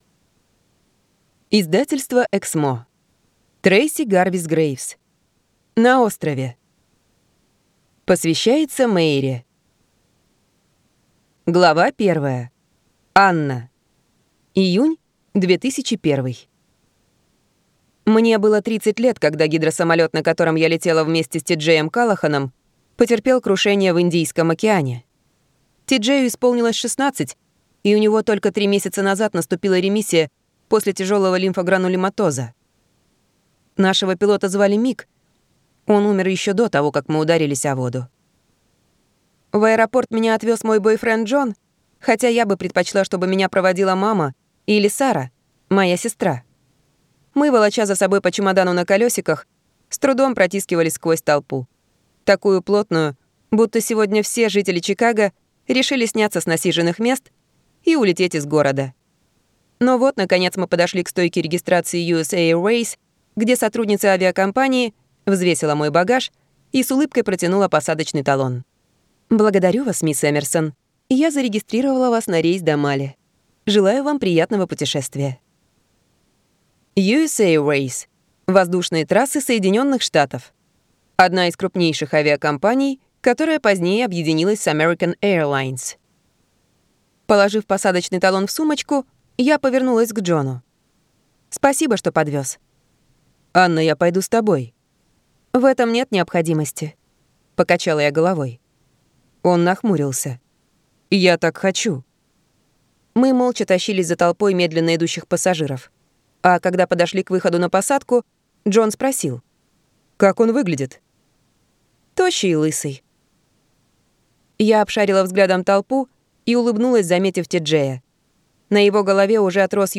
Аудиокнига На острове - купить, скачать и слушать онлайн | КнигоПоиск